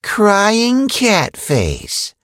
kit_hurt_vo_08.ogg